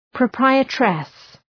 {prə’praıətrıs}